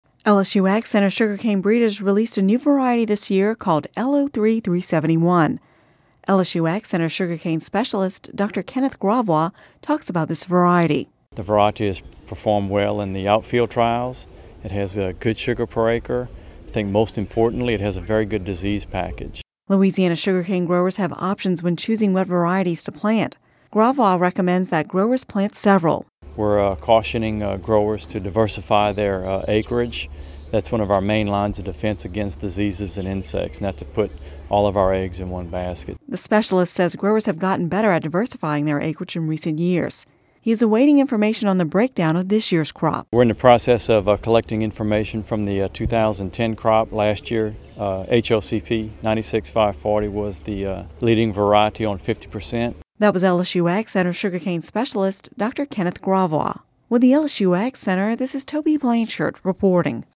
(Radio News 08/02/10) LSU AgCenter sugarcane breeders released a new variety this year called L 03-371.